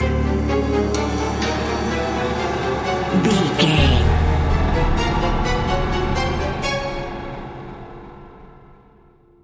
Atonal